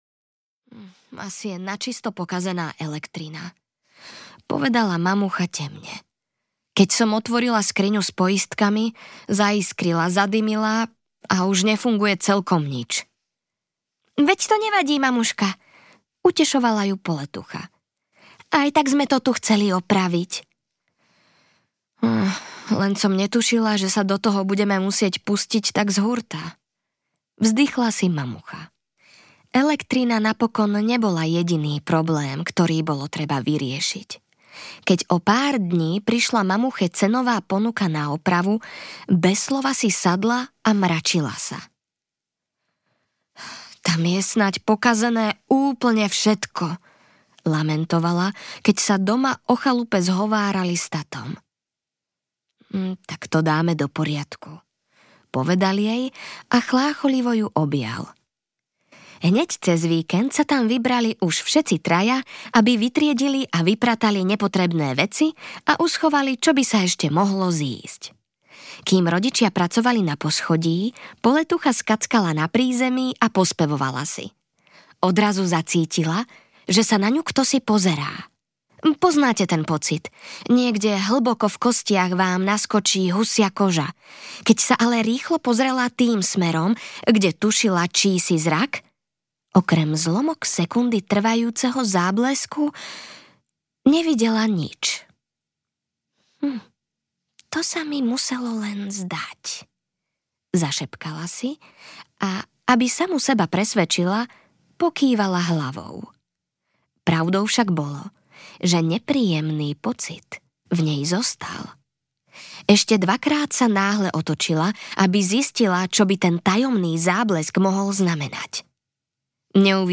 Temnotvor audiokniha
Ukázka z knihy